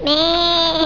Schafsounds
Lammchen:                Süßmääähhhh               (wav 10 KB)